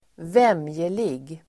Uttal: [²v'em:jelig]